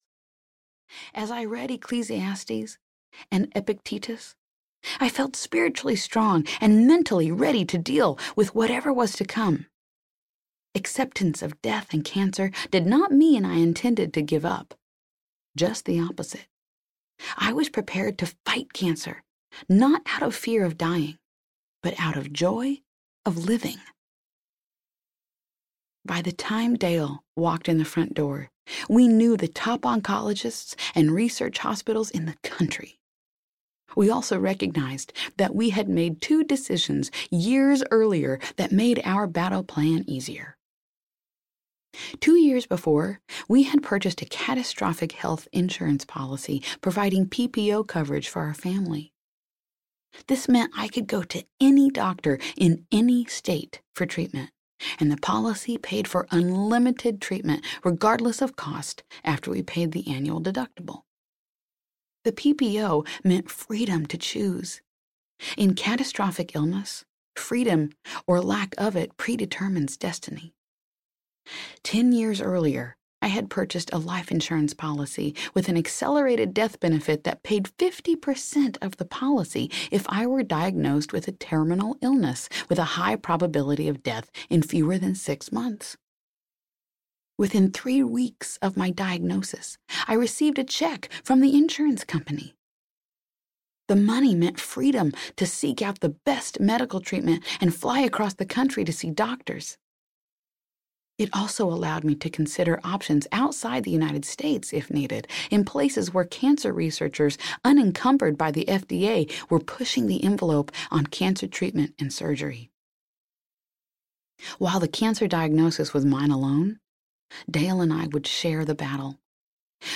The Mission Walker: I was given three months to live… Audiobook
Narrator